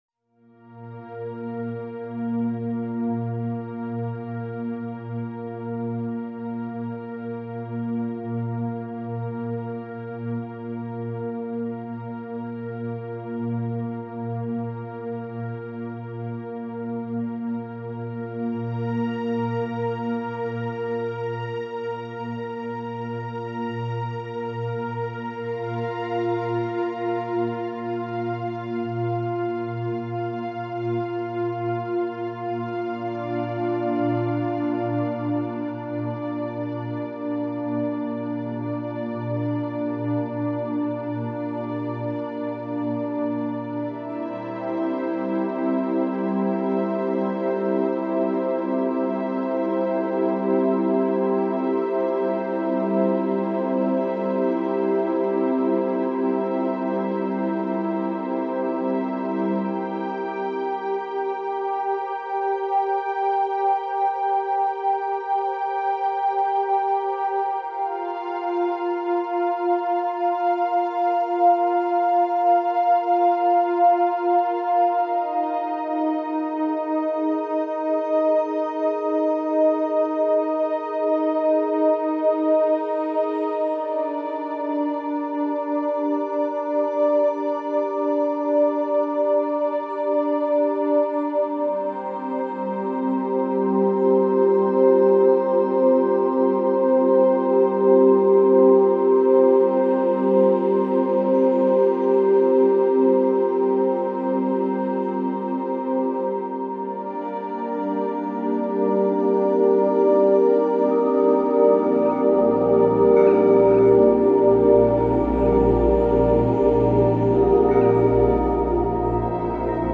La funcion principal de este sonido es activar la glandula pineal , el sonido se transporta por la frecuencia de 936Hz activando esta glandula de vital importancia en el organismo humano.
SI – 936Hz Mastery and Pineal Gland Activator Solfeggio Meditation